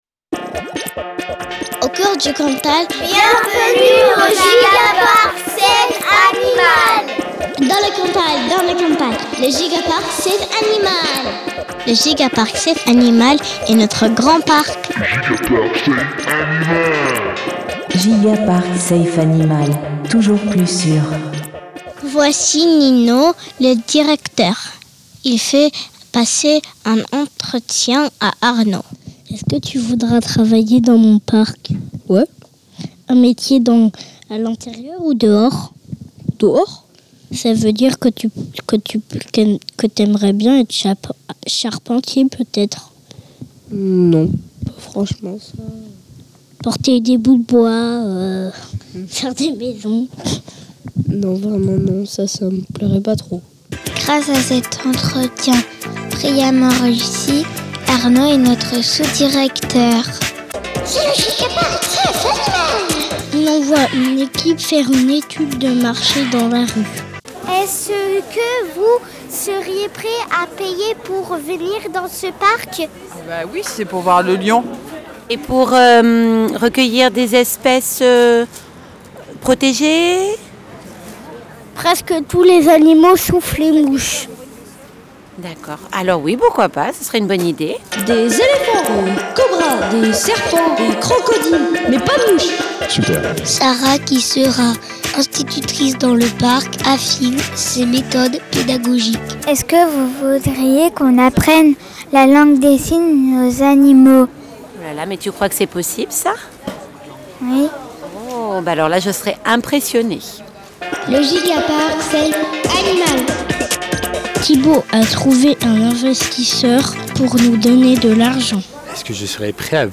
Lecture franco-allemande